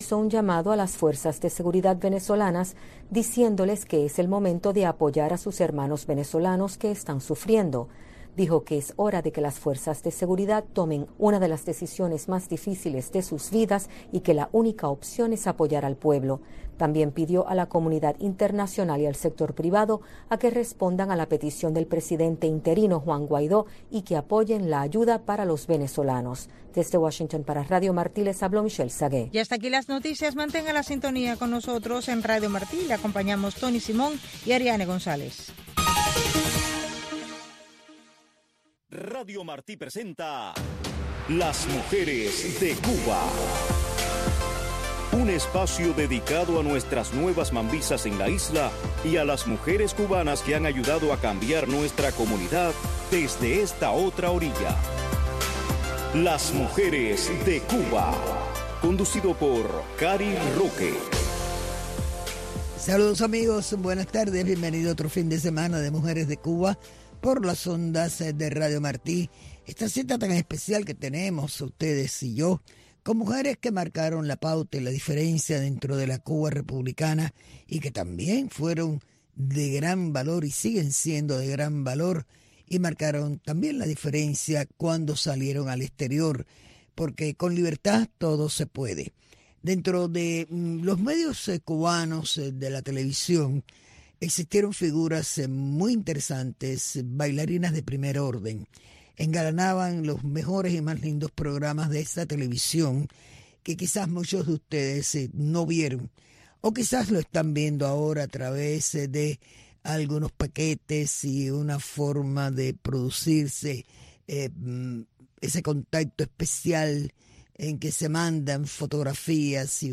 Un programa narrado en primera persona por las protagonistas de nuestra historia.